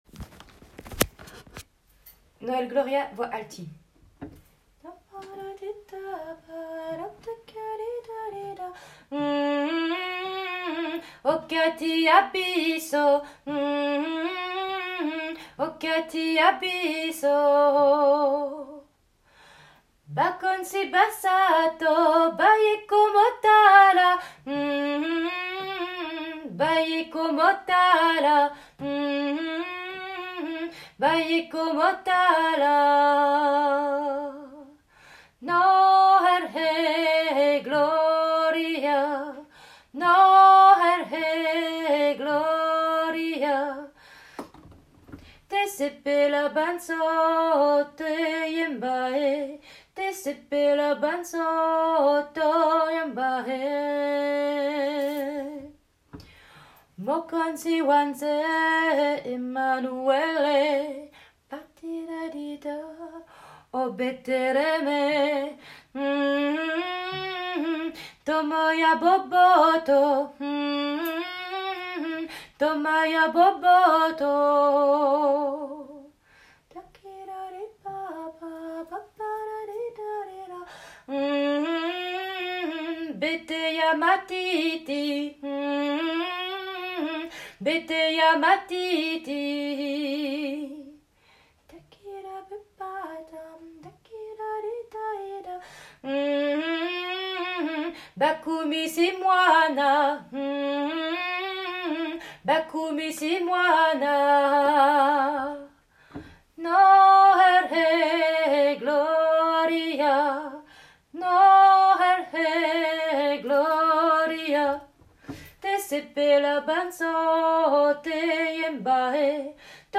Alti
No--l-Gloria---Alti.m4a